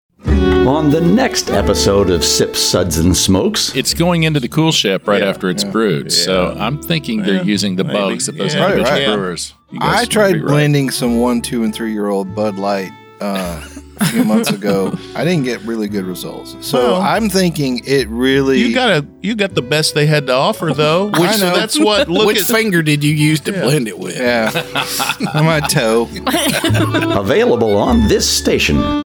(h:mm:ss) 0:00:29   Sips, Suds, & Smokes That is a lot of plum PROMO Download (0)
192kbps Mono